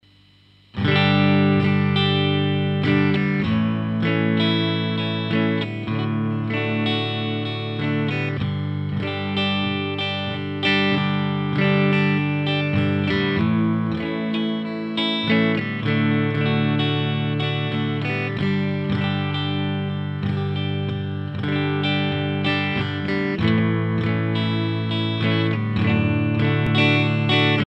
Simple clean sound help.
View attachment untitled.mp3 Me, I think its weak a little bit but don't know what to do to get it a little more character.
I play a USA Fender Tele into a Fender Blues Dekluxe re-issue.
It's miked with a Shure SM57 so the equipments right, or right enough.
The settings on my amp are volume,2 - treble,9 - bass,4 - mid,6 - presence,6.